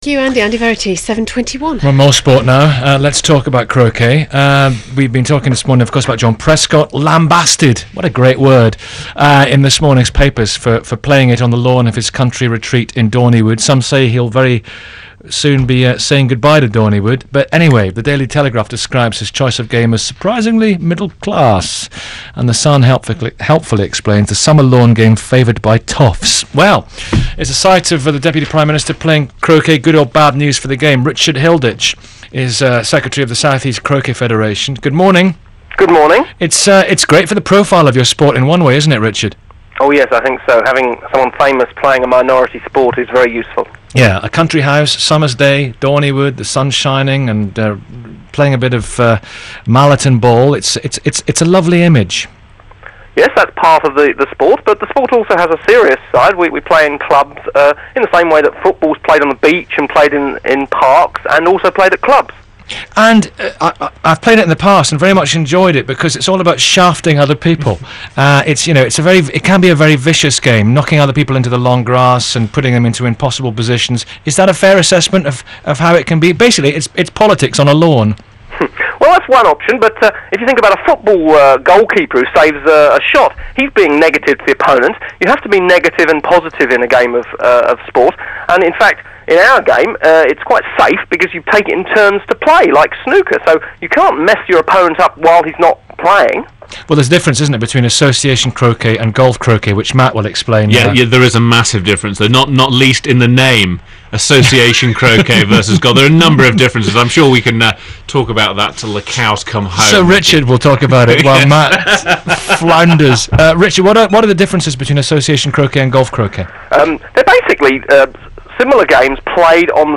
BBC Radio 5 Live Interview